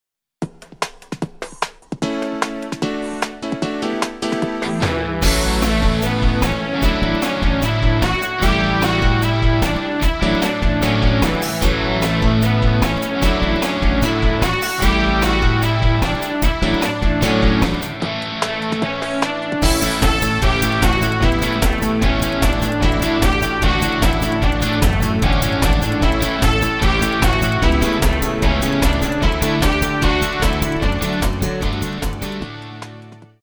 Gitarre
Genre: Rockpop
Qualität: MP3, Stereo